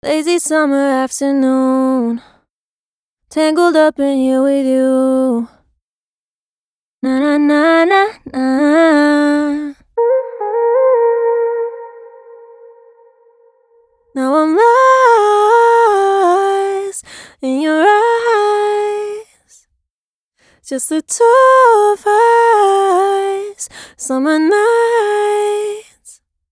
•140+ Rnb vocal phrases/runs
•Dry vocals + processed
Demo